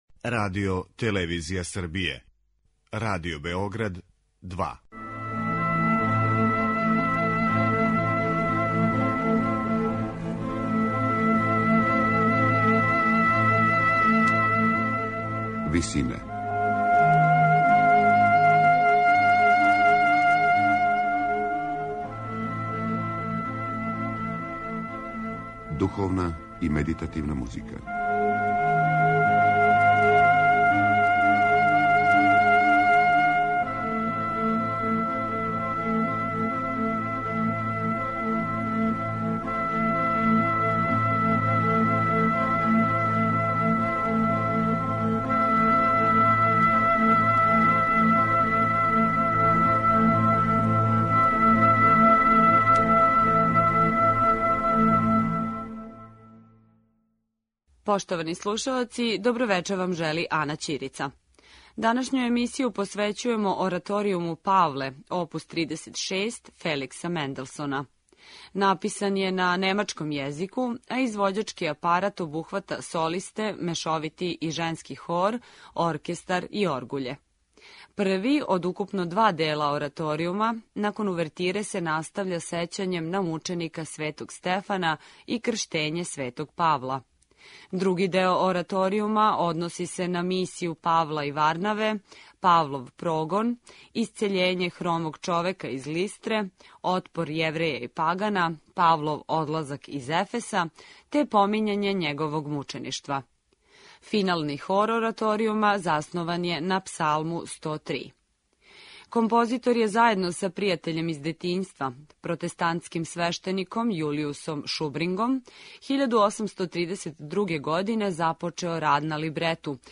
Емисија је посвећена остварењу које је Менделсон приредио за солисте, мешовити и женски хор, оркестар и оргуље.